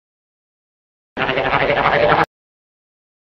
Tombone Gobble
The sound used when a cartoon character shakes their head.
the-original-trombone-gobble.mp3